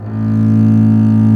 Index of /90_sSampleCDs/Roland - String Master Series/STR_Cb Bowed/STR_Cb3 Arco nv
STR DBLBAS01.wav